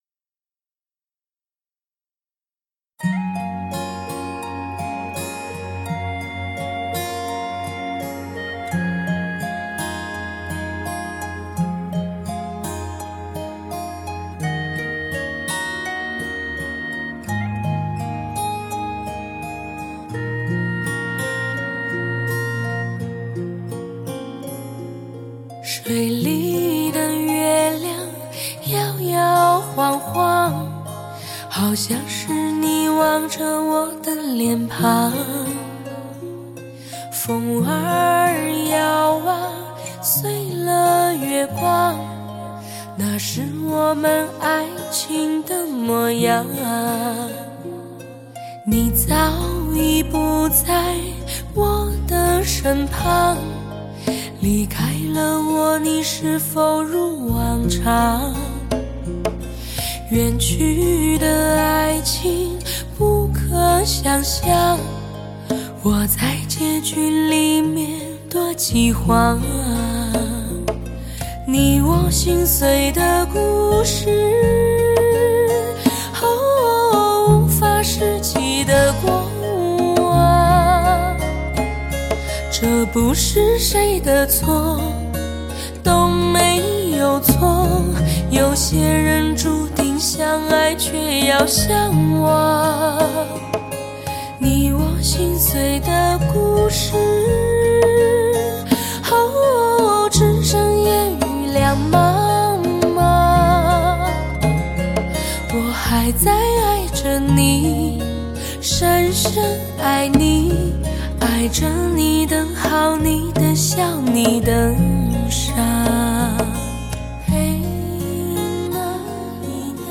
柔声清调